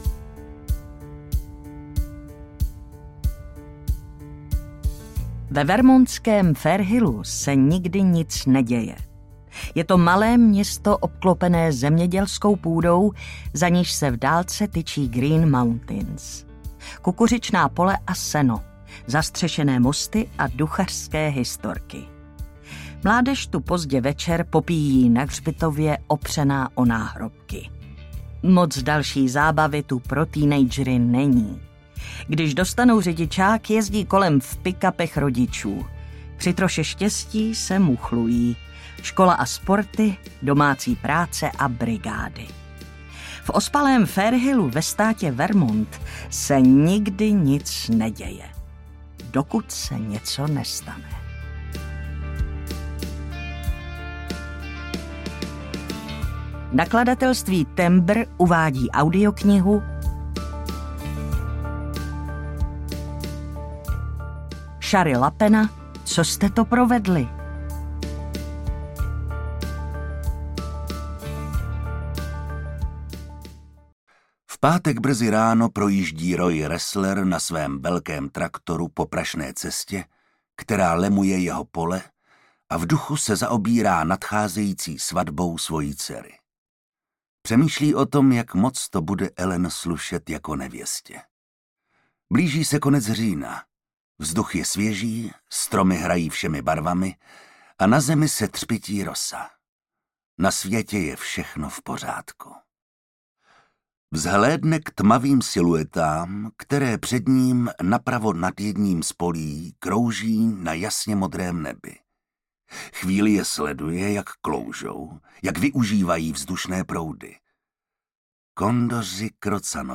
Co jste to provedli? audiokniha
Ukázka z knihy